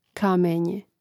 kàmēnje kamenje